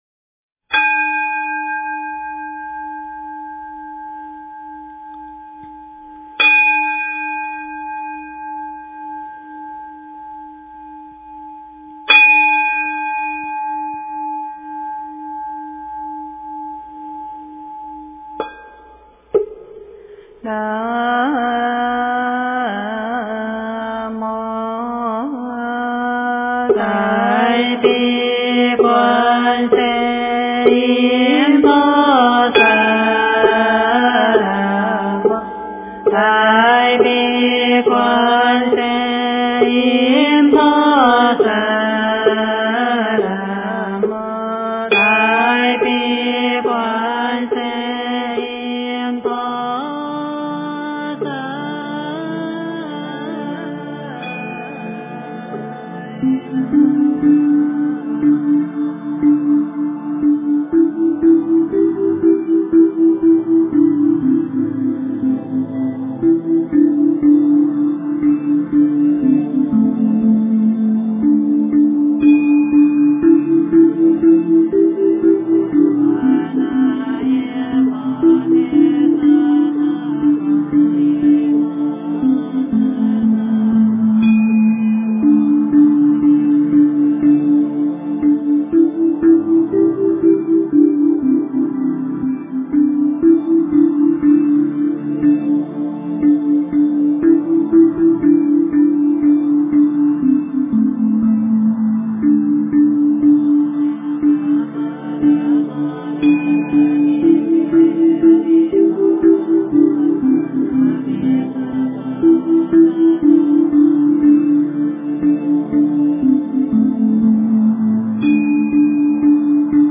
大悲咒 诵经 大悲咒--色空鼓 点我： 标签: 佛音 诵经 佛教音乐 返回列表 上一篇： 大悲咒-车载 下一篇： 大悲咒-藏音 相关文章 佛说大乘无量寿庄严清净平等觉经（女声读诵） 佛说大乘无量寿庄严清净平等觉经（女声读诵）--未知...